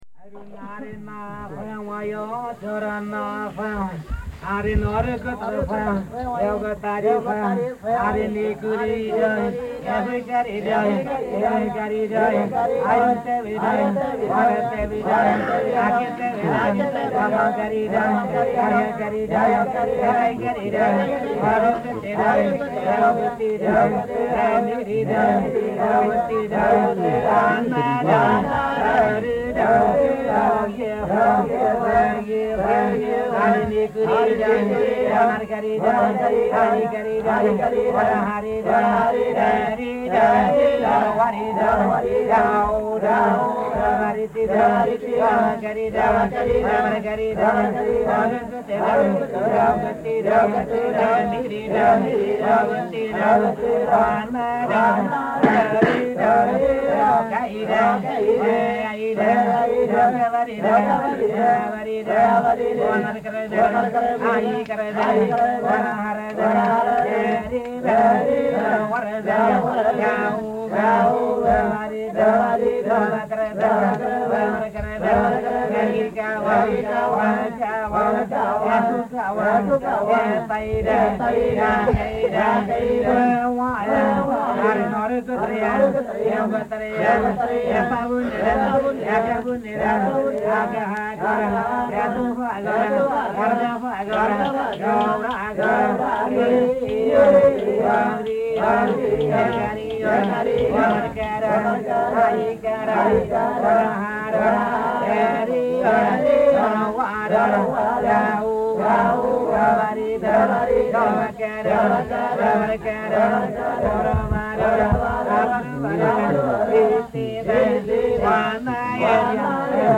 U'wa Cobaria dance from the Andes in north-east Colombia.